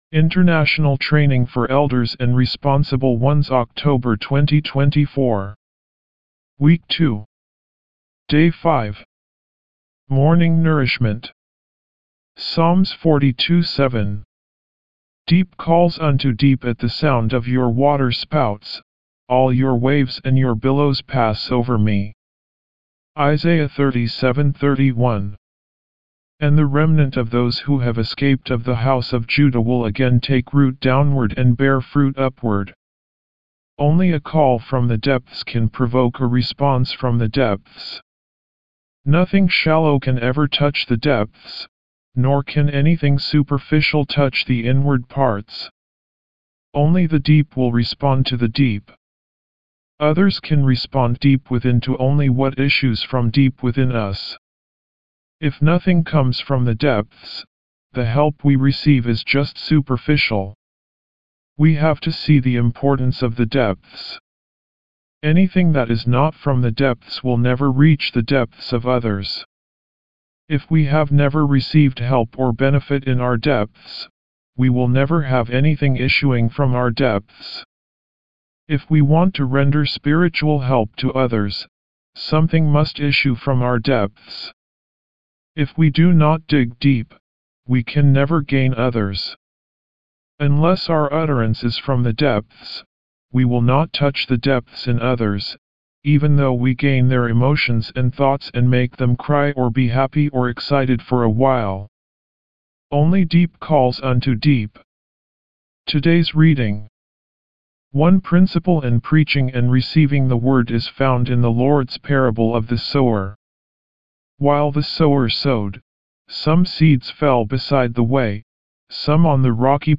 D5 English Rcite：